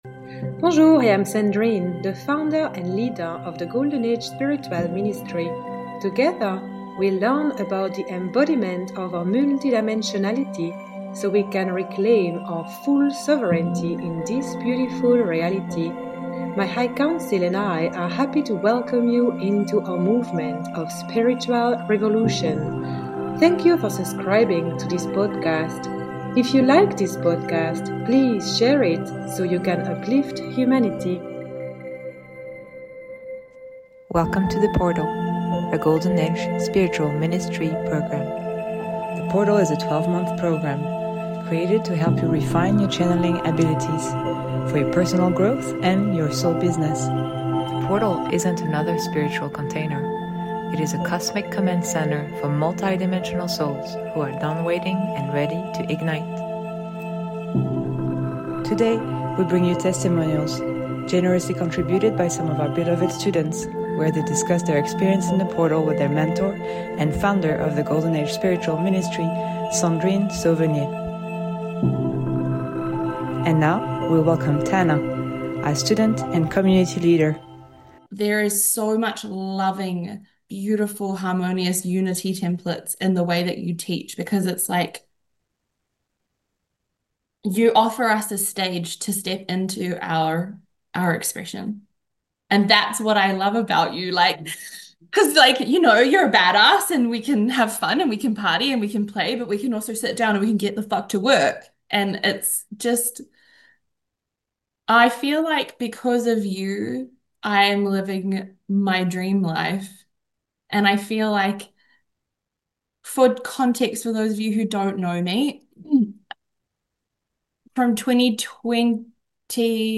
Our beloved students share their experience during a discussion with our mentor